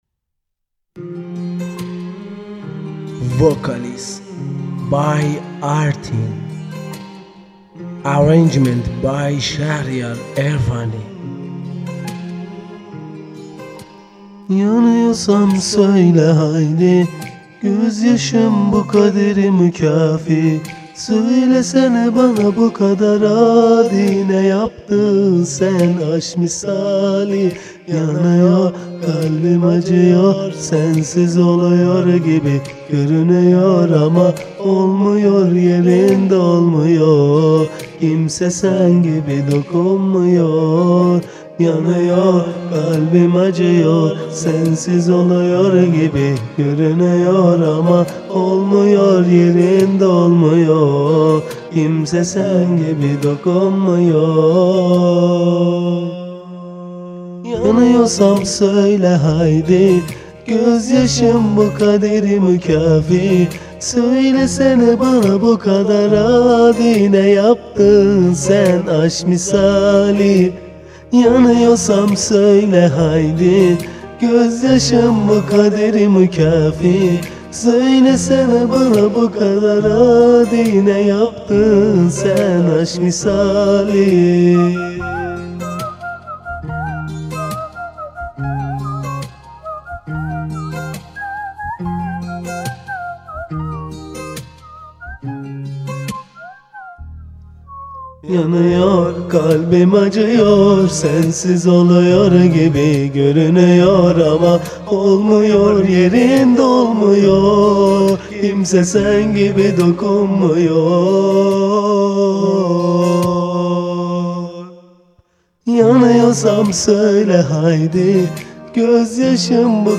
آهنگساز و گیتار
آهنگ زیاد خوندم ولی این سبک با گیتار اولین باره انشالله دمو رو شب لینک میکنیم گوش کنید و نظر بدید.
عجب صدایی 🤤